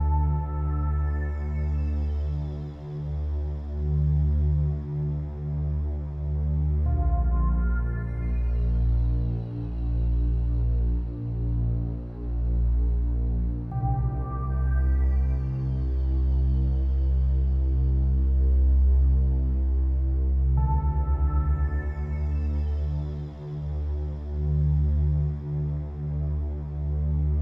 VTS1 25 Kit Melody & Synth